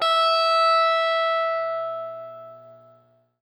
SPOOKY    AV.wav